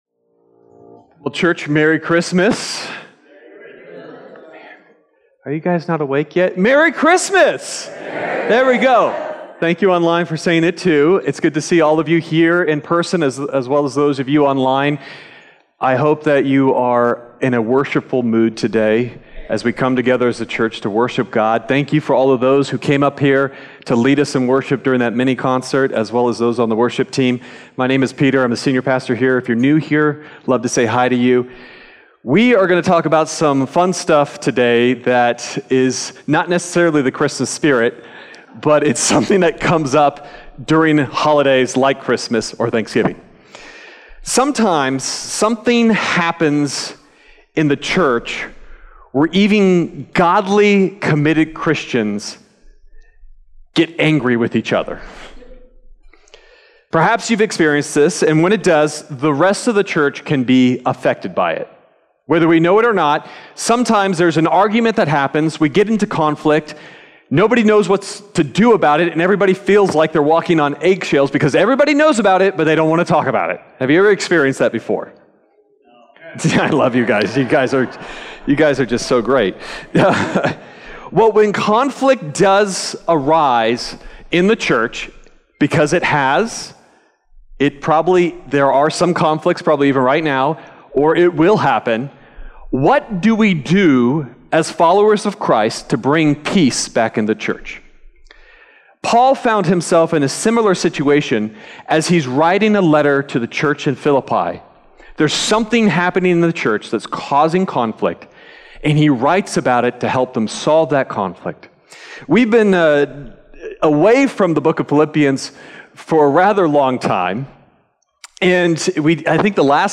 Sermon Detail
December_15th_Sermon_Audio.mp3